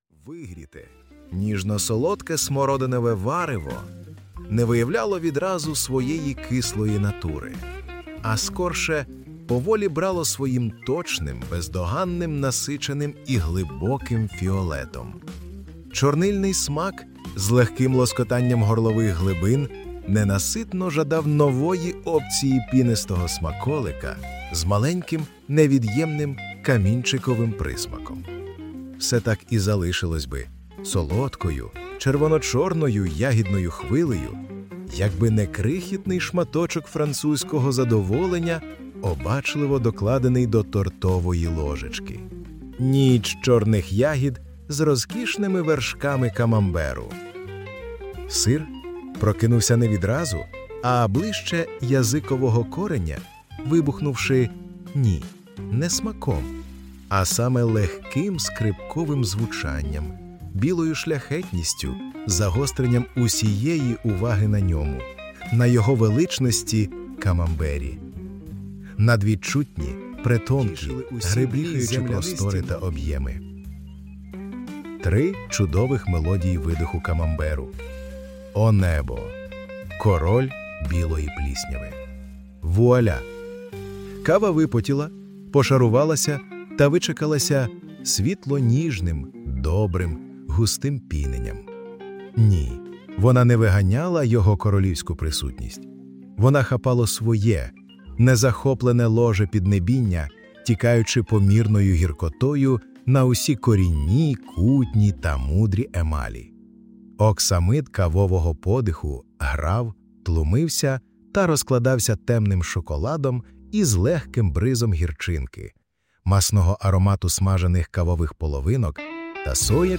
Camembert_Blues_Violin_CleanedFinal.mp3